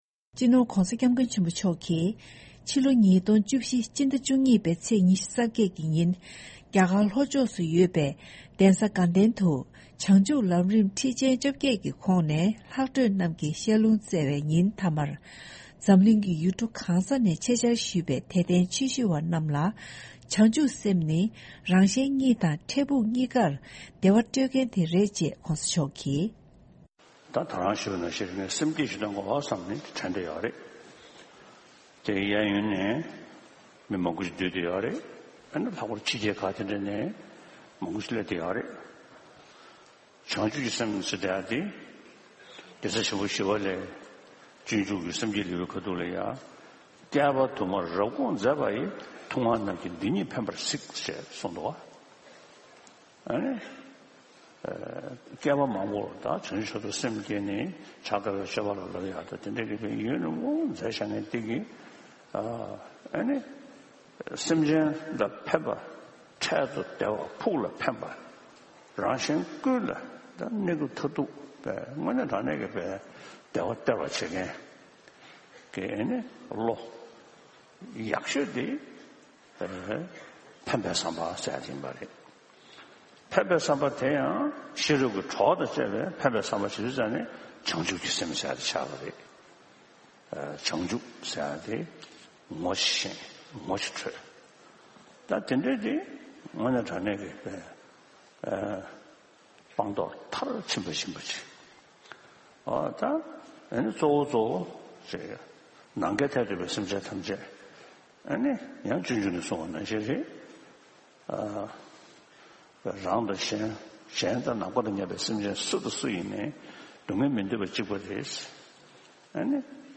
ཕྱི་ལོ་༢༠༡༤འི་ལོ་མཇུག་ཏུ་སྤྱི་ནོར་༧གོང་ས་༧སྐྱབས་མགོན་ཆེན་པོ་མཆོག་གིས་བདུན་ཕྲག་གཅིག་མིན་ཙམ་ཞིག་གི་རིང་རྒྱ་གར་ལྷོ་ཕྱོགས་བོད་མིའི་གཞིས་སྒར་མོན་རྒུ་སྡོད་རྒུ་གླིང་དུ་ཡོད་པའི་དགའ་ལྡན་དུ་བྱང་ཆུབ་ལམ་རིམ་ཁྲིད་ཆེན་༡༨ཀྱི་ལྷག་འཕྲོས་ཀྱི་བཤད་ལུངས་གནང་བའི་ཉིན་མཐའ་མར་དད་ལྡན་ཆོས་ཞུ་བ་རྣམས་ལ་སེམས་བསྐྱེད་ཀྱི་སྡོམ་པ་གནང་བའི་སྐབས་ བྱང་ཆུབ་སེམས་དང་ སྟོང་ཉིད་ཀྱི་ལྟ་བ་གཉིས་ཉམས་སུ་ལེན་ན་ ཕན་ཡོན་ཧ་ཅང་ཆེན་པོ་ཡོད་པའི་སྐོར་གསུངས་པ་ཞིག་གསན་རོགས་གནང་།